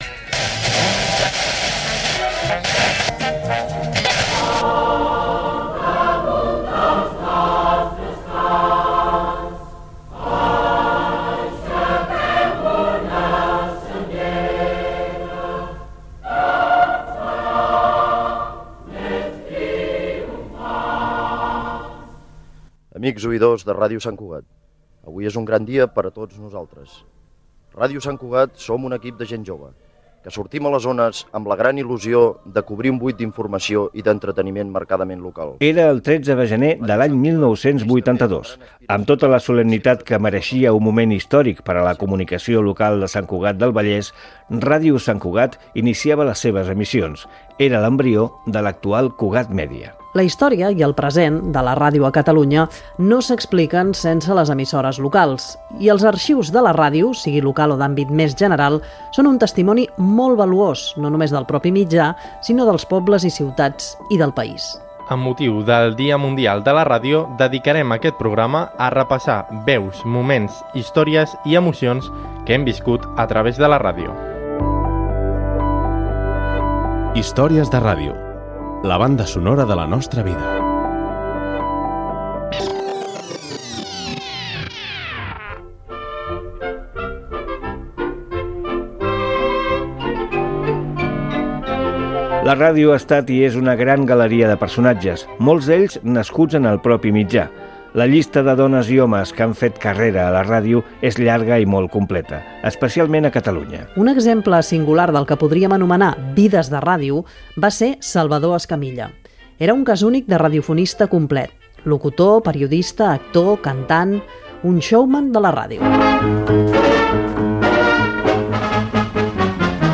Inici del programa emès amb motiu del Dia Mundial de la Ràdio 2023. Recordant l'inici de les emissions de Ràdio Sant Cugat, a Salvador Escamilla i Radio-scope i a Núria Feliu.
Divulgació